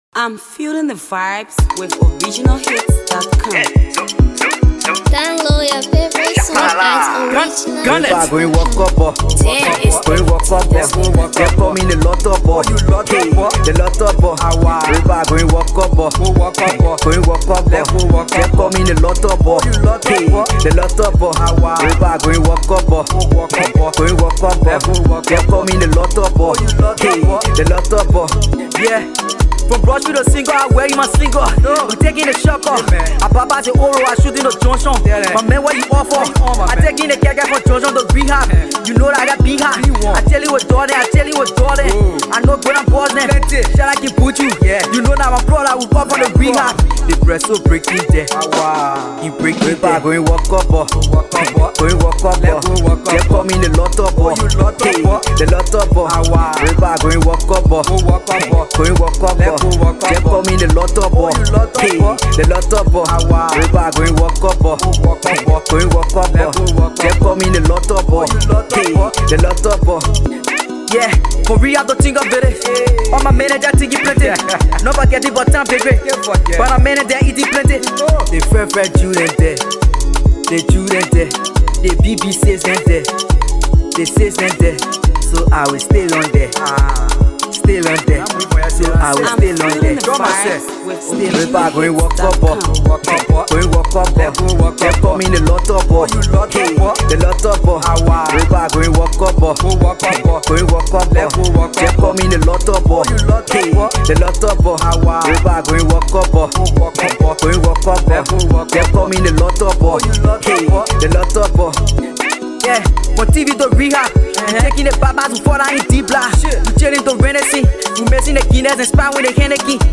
Nice danceable banger from talented Liberian artist